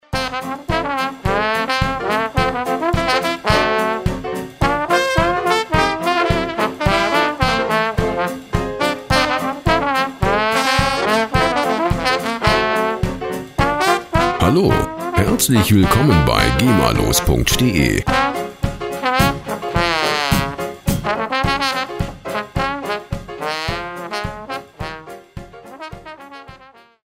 Der Klang der Musikinstrumente
Instrument: Zugblasinstrument
Tempo: 107 bpm